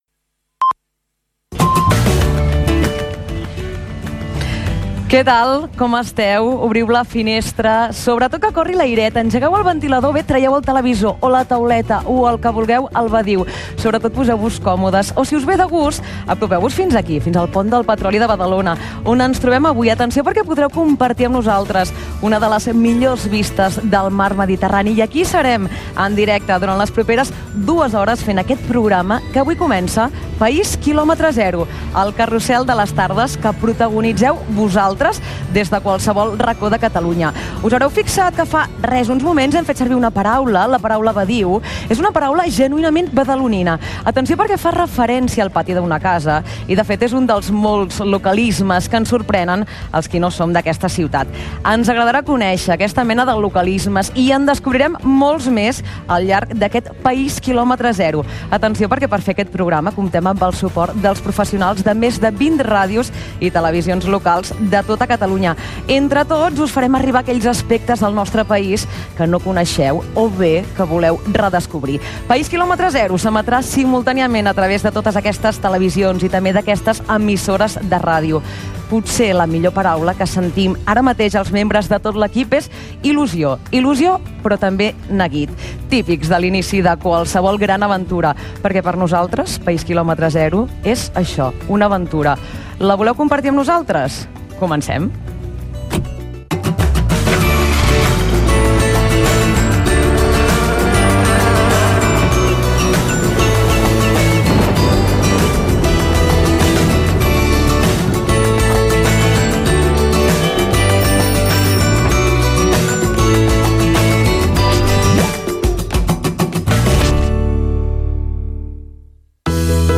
Presentació del primer programa fet des del pont del petroli de Badalona.
Entreteniment